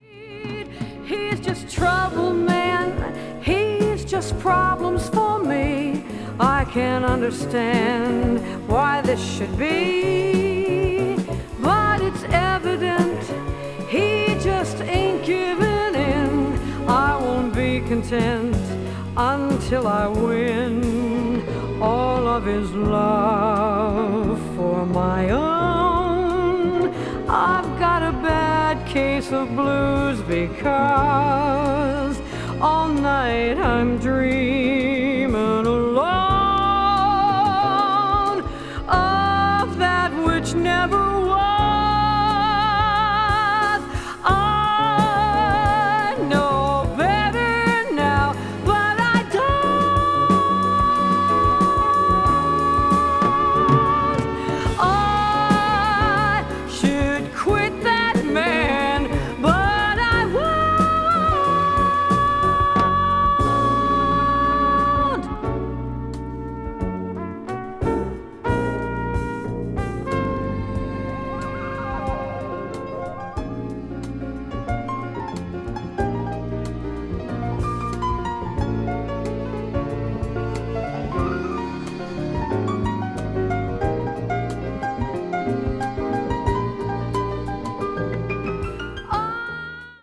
media : VG+/VG+(わずかなチリノイズ/一部軽いチリノイズが入る箇所あり,再生音に影響ない薄い擦れあり)
jazz ballad   jazz standard   jazz vocal   mellow jazz